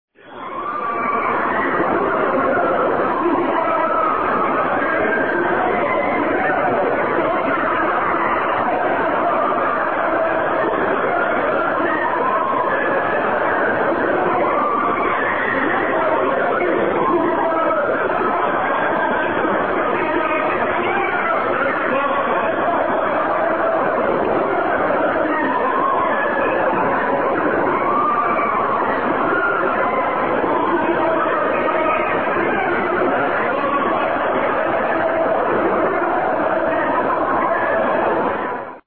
Гул, стоны, скрежет и другие жуткие эффекты создадут атмосферу мистики или станут основой для творческих проектов.
грешные люди варятся в кипящем котле и вопят